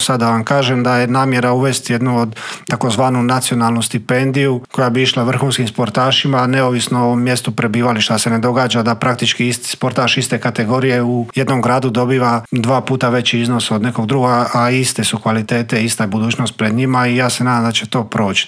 ZAGREB - U današnjem Intervjuu tjedna Media servisa gostovao je posebni savjetnik ministrice turizma i sporta, Josip Pavić, olimpijski pobjednik, svjetski i europski prvak, te osvajač Lige prvaka.